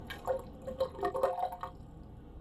9507 Drone Ambiance " 25 - 声音 - 淘声网 - 免费音效素材资源|视频游戏配乐下载
水声潺潺